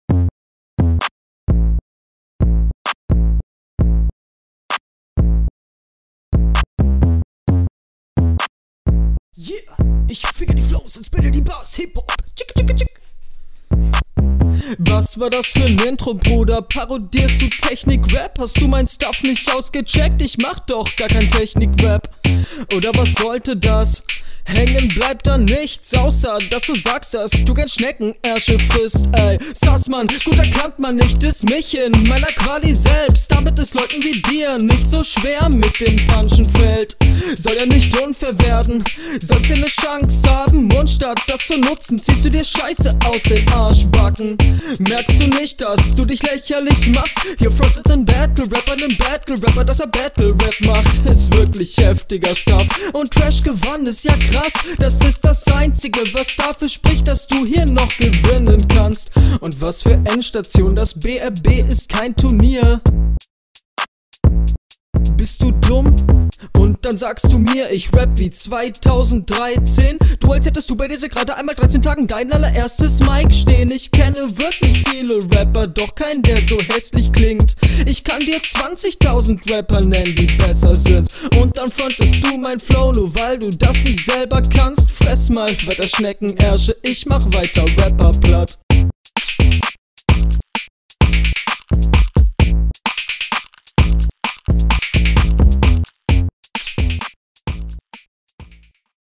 Stimmlich klingt das sehr selbstironisch und wirkt authentischer als beim Gegner.